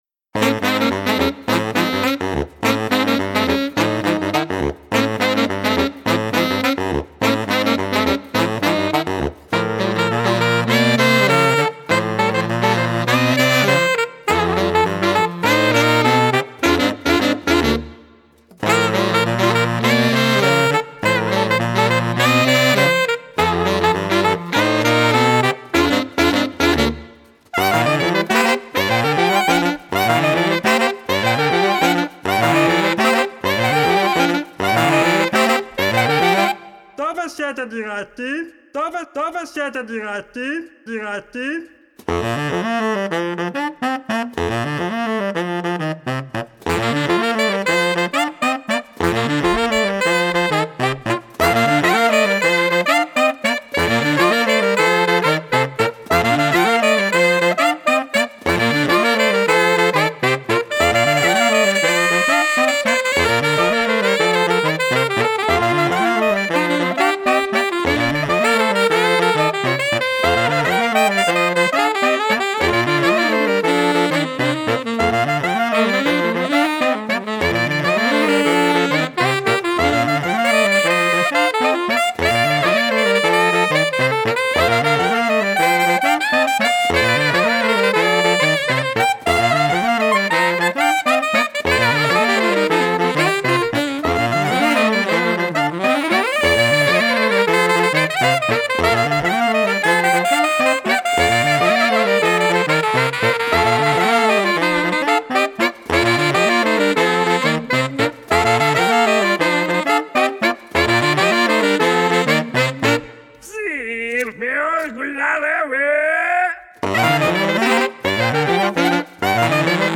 Recorded at Only Music Studio, Bruino, January 12 & 13 2004.